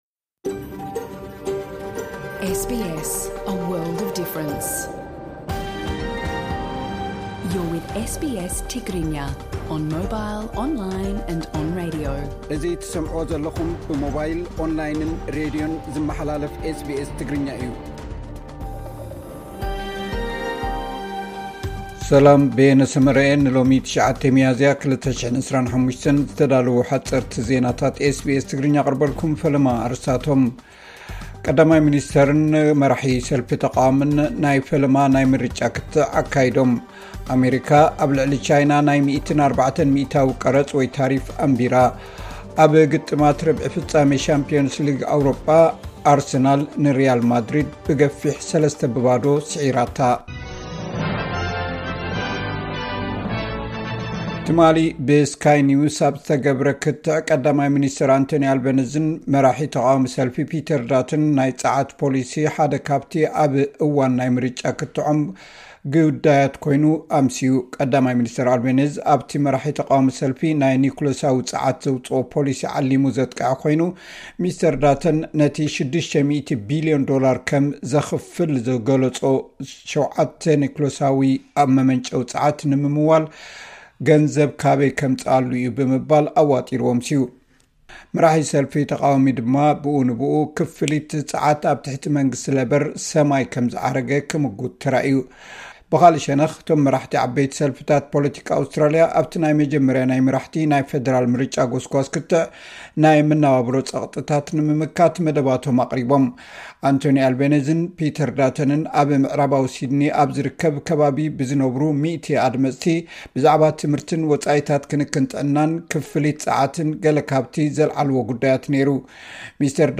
ሓጽርቲ ዜናታት ኤስ ቢ ኤስ ትግርኛ (09 ሚያዝያ 2025)